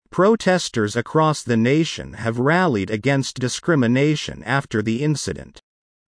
このままの速度でお聞きください。
ディクテーション第1問
【ノーマル・スピード】